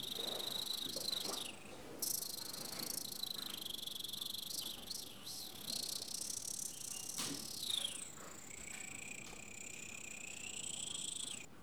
sifflement-oiseau_01.wav